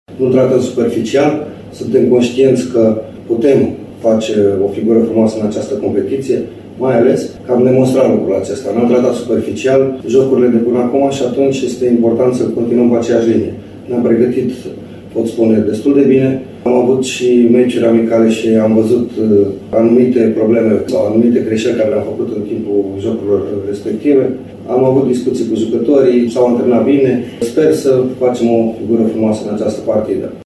Stoican admite că echipa pe care o conduce nu va trata, sub nicio formă, cu superficialitate jocul cu echipa lui Alexandru Pelici, cea care se află şi în luptă directă pentru un loc de baraj cu concitadina Universitatea Alba Iulia şi cu Gloria Bistriţa, într-una din cele mai echilibrate serii din Liga a treia.